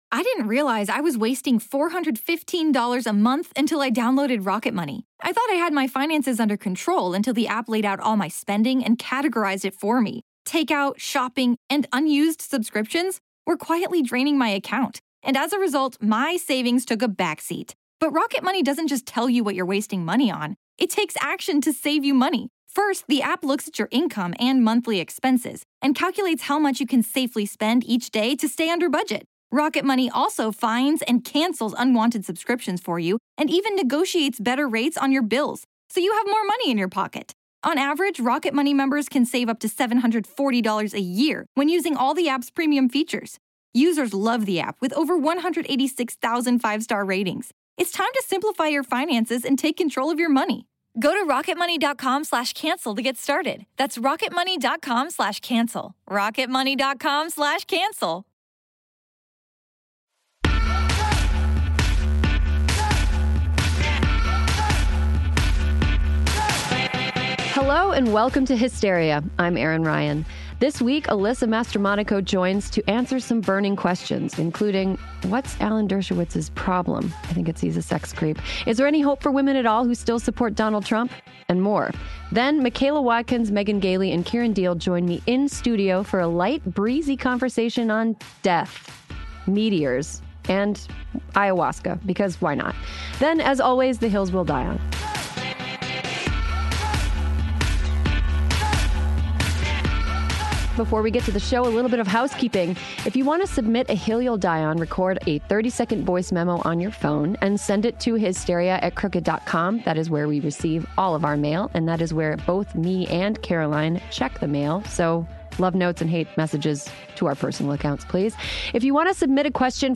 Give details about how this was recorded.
join in-studio to for a light, breezy conversation on death, meteors, and ayahuasca. Then, as always, the hills we'll die on.